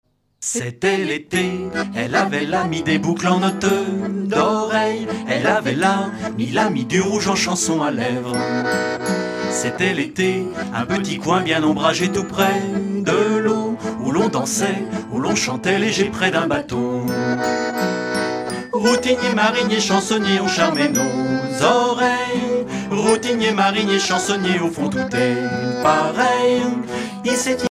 Genre strophique
Concert donné en 2004
Pièce musicale inédite